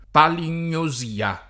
palingnosia [ palin’n’o @& a ] s. f. (med.)